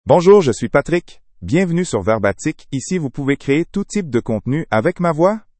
Patrick — Male French (Canada) AI Voice | TTS, Voice Cloning & Video | Verbatik AI
MaleFrench (Canada)
Patrick is a male AI voice for French (Canada).
Voice sample
Patrick delivers clear pronunciation with authentic Canada French intonation, making your content sound professionally produced.